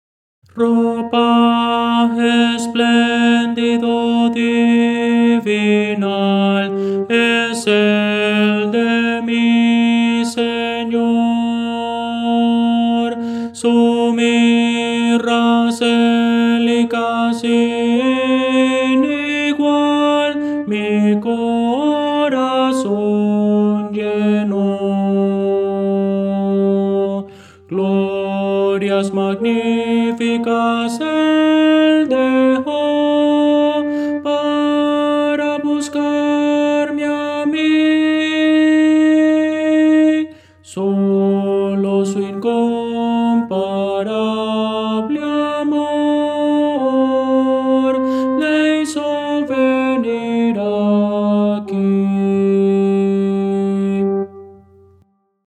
Voces para coro
Tenor
Audio: MIDI